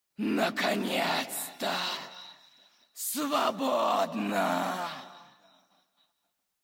Когда босс применяет какую-нибудь свою способность он издает при этом определенные звуки или говорит «дежурные» фразы, порой весьма раздражающие… smile Рассмотрим это на примере босса Цитадели Ледяной Короны – Синдрагосы.